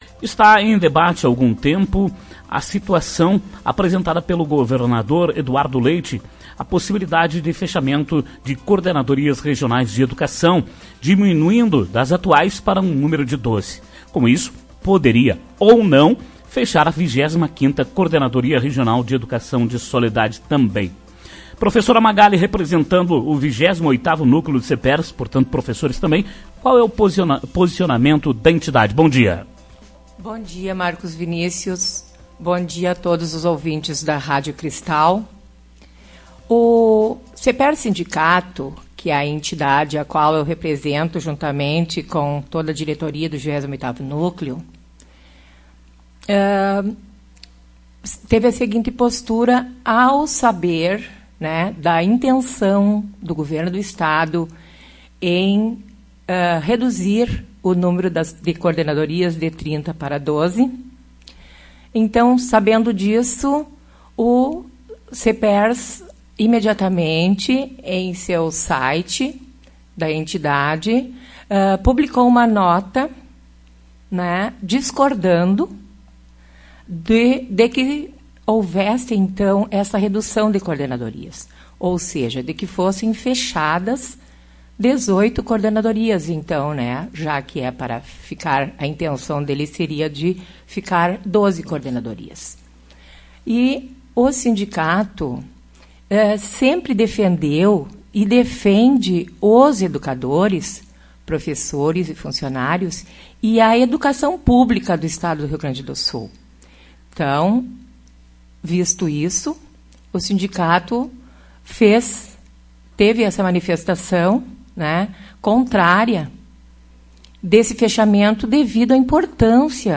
participa da programação